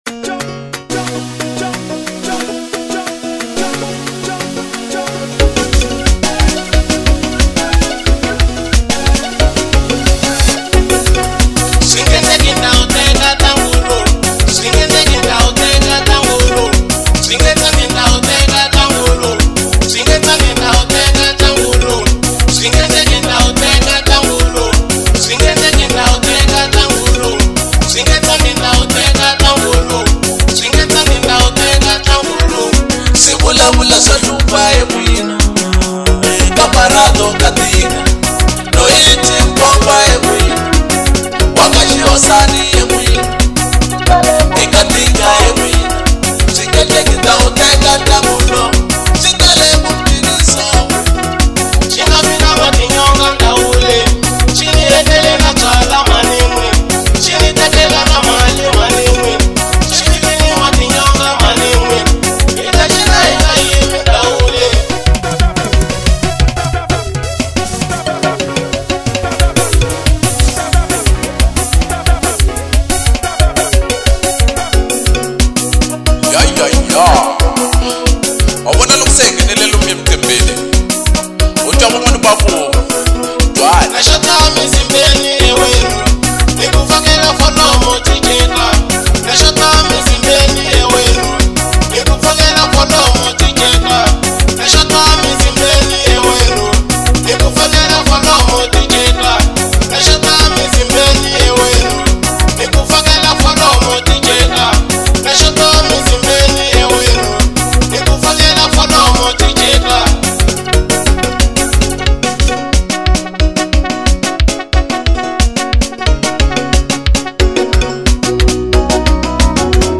04:10 Genre : Xitsonga Size